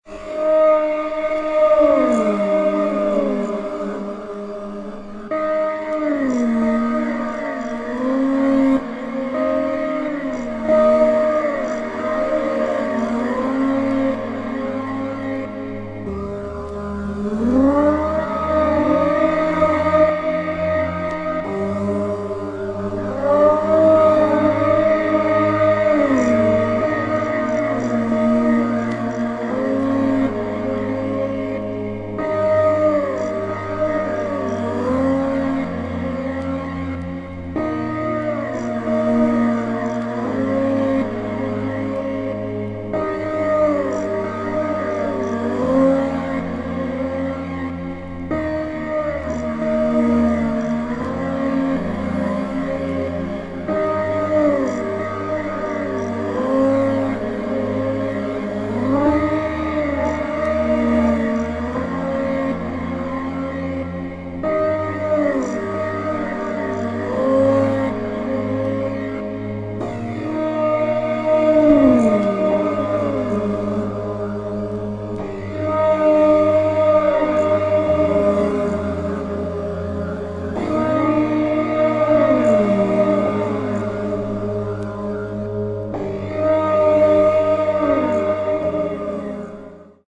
自身のヴォイスを空間的に処理した